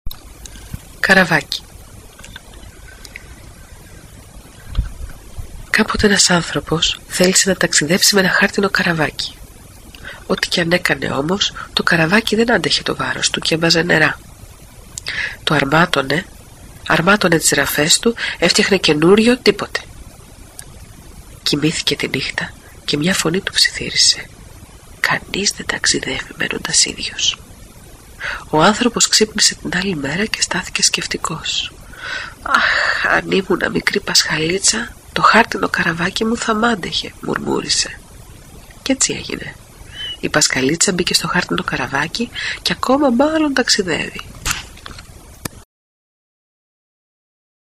αφήγηση
sxoleio thalassa tsanda λεξο ιστορίες